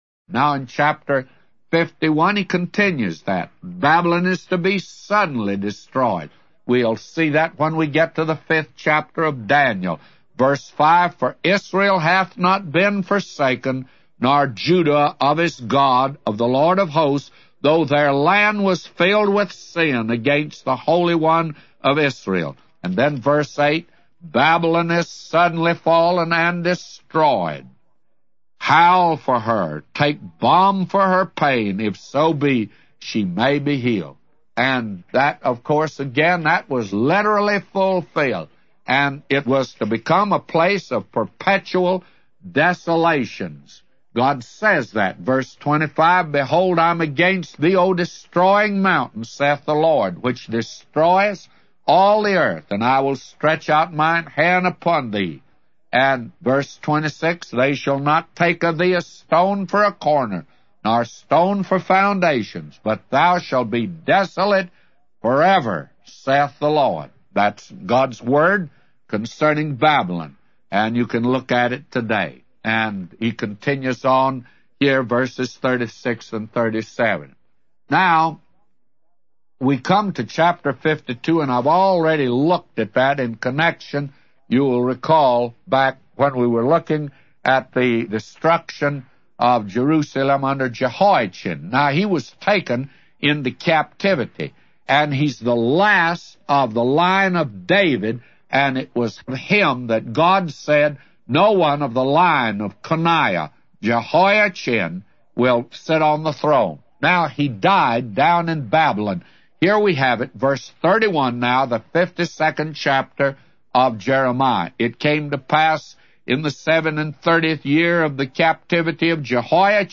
A Commentary By J Vernon MCgee For Jeremiah 51:1-999